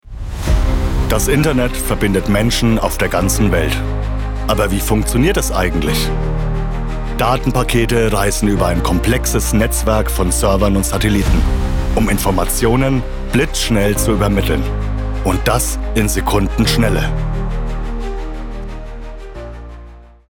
Dokumentationen: „Web-Doku“